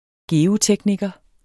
Udtale [ ˈgeːoˌtεgnigʌ ]